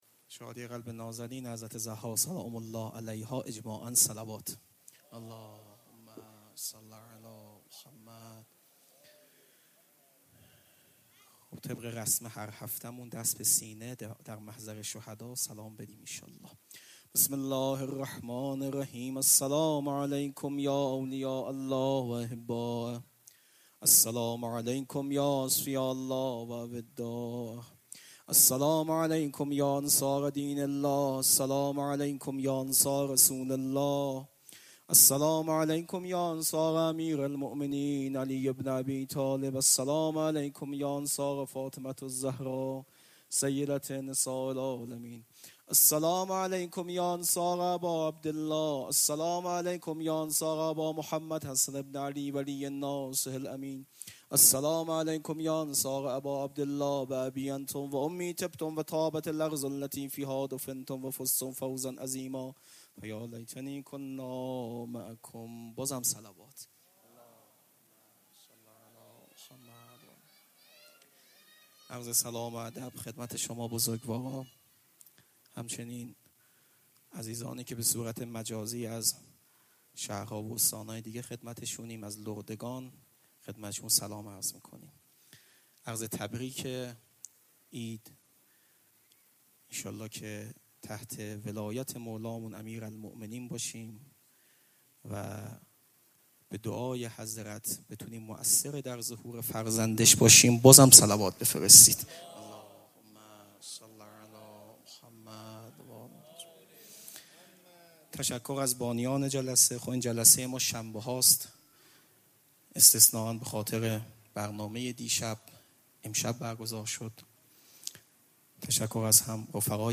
صوت جلسه راهبردی